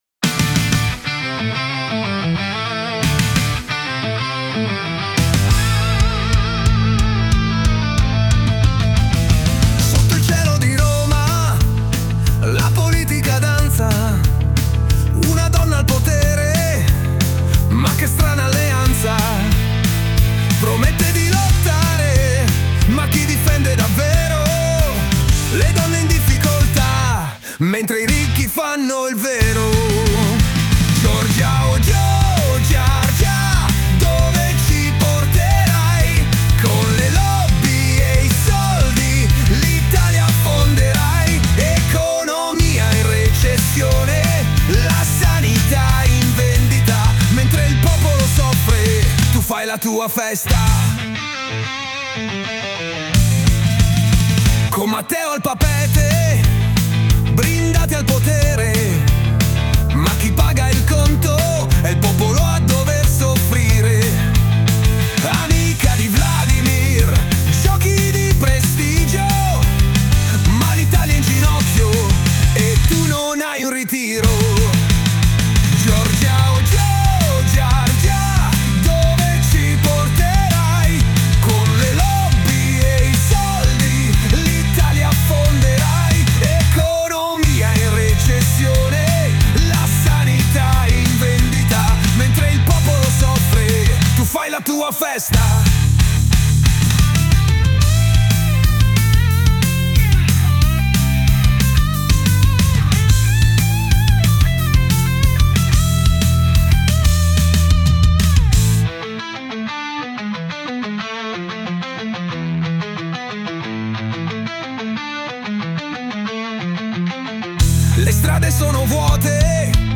A grandissima richiesta (😉) ne ho sfornata un'altra sulla nostra presidente del consiglio, stesso processo illustrando precedentemente, aggiustando il testo un po' di più perché il prompt era molto più grezzo, e ho aggiustato lo stile su suno